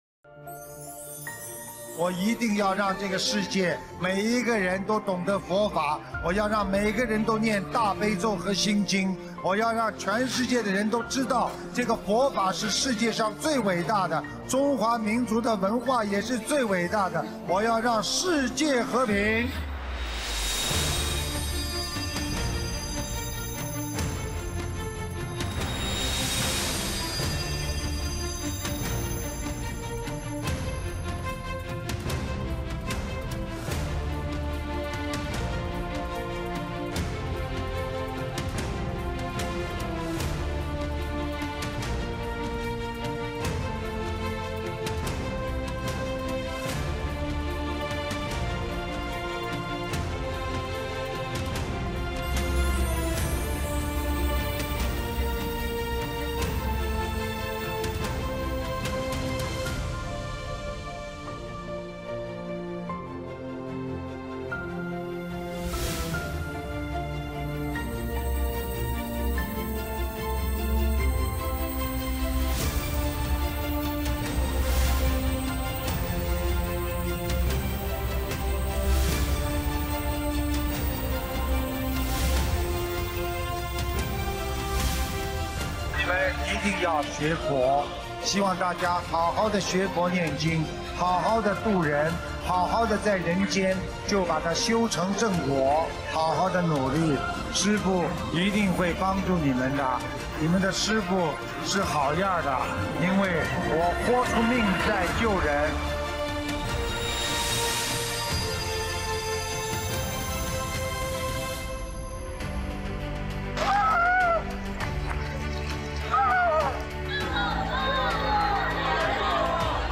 首页 >>弘法视频 >> 新闻报道
【〔视频〕佛學會訪談！